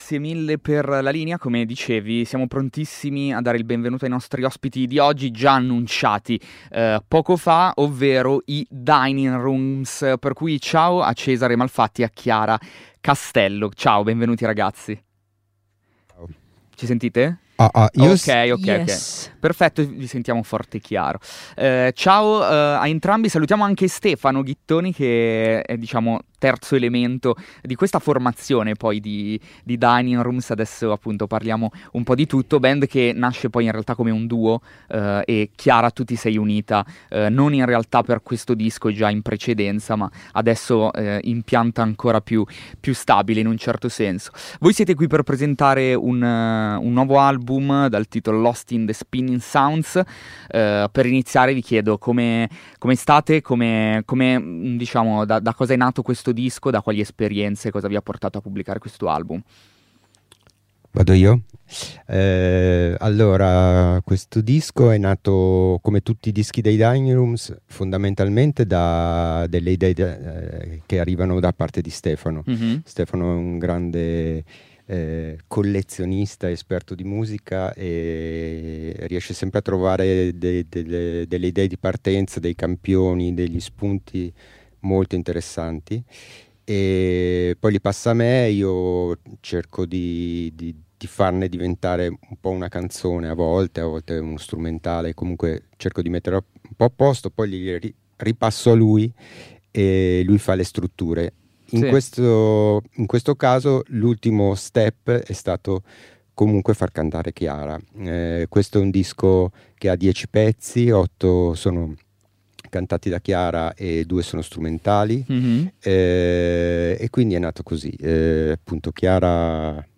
Ascolta l’intervista ai Dining Rooms.